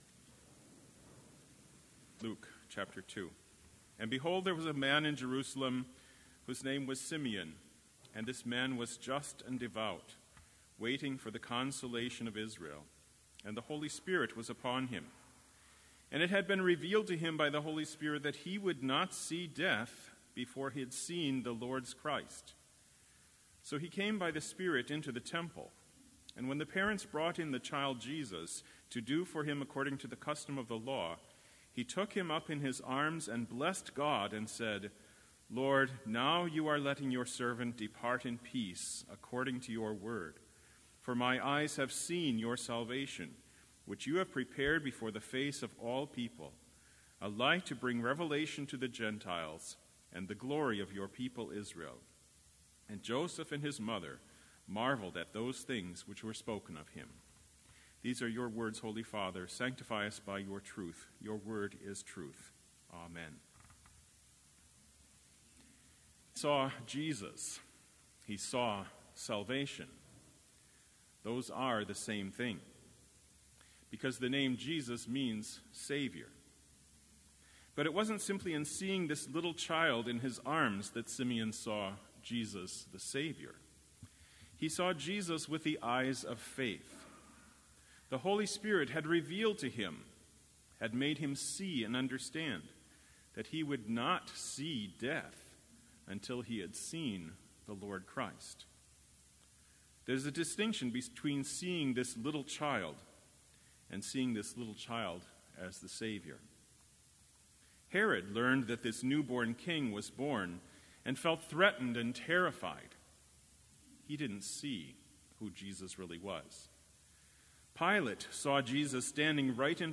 Sermon Only
This Chapel Service was held in Trinity Chapel at Bethany Lutheran College on Wednesday, January 9, 2019, at 10 a.m. Page and hymn numbers are from the Evangelical Lutheran Hymnary.